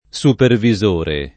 [ S upervi @1 re ]